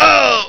Worms speechbanks
Ooff2.wav